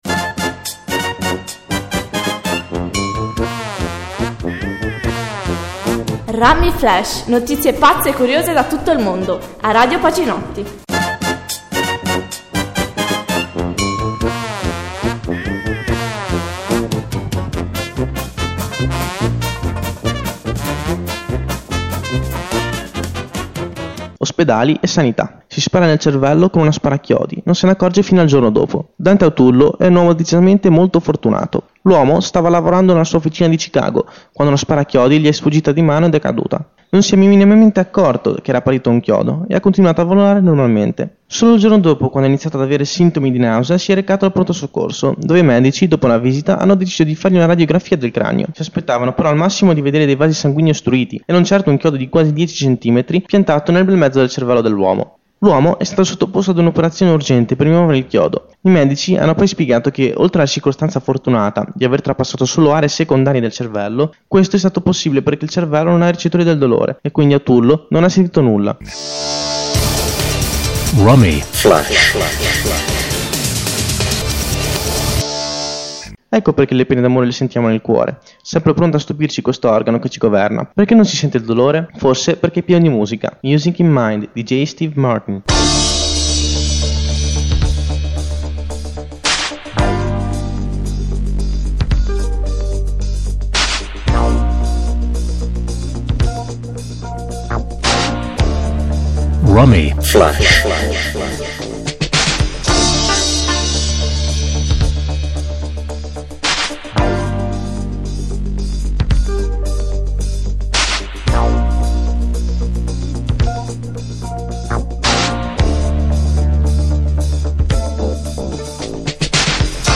Notizie curiose e brano di musica con relazione alla notizia